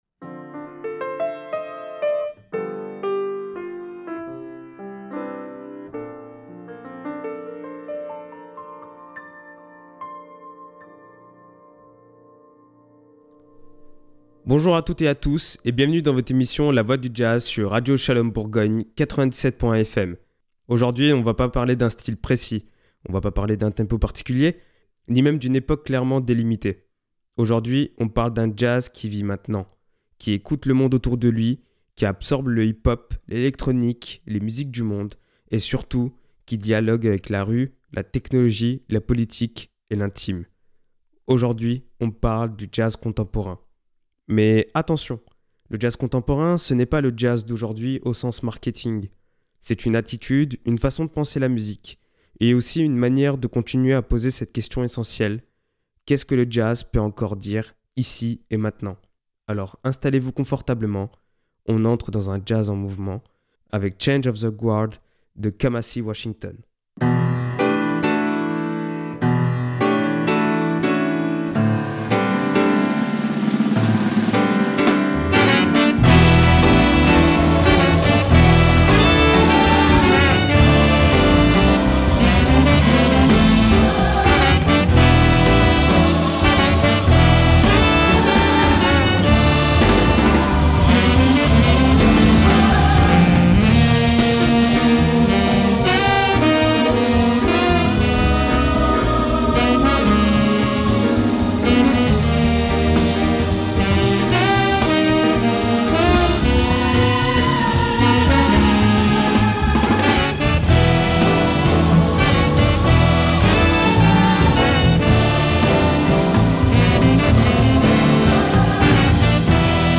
LA VOIE DU JAZZ EP 15 - Jazz Contemporain